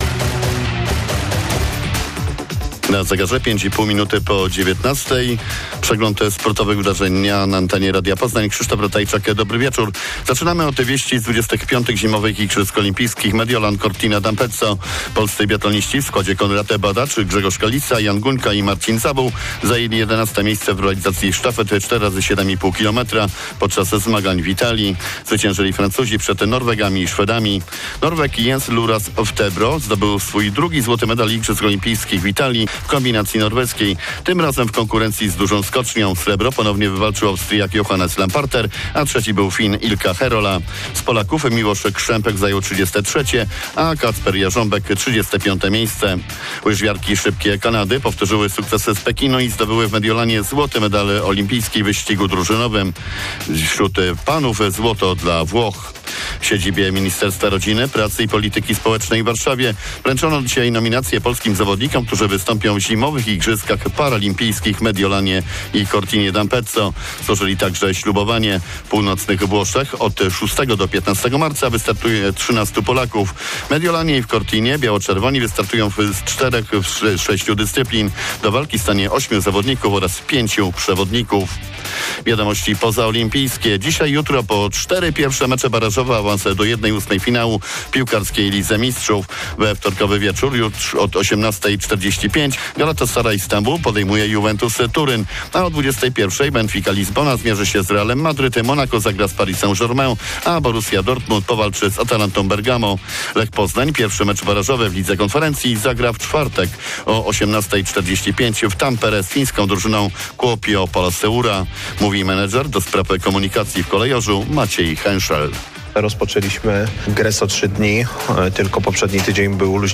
17.02.2026 SERWIS SPORTOWY GODZ. 19:05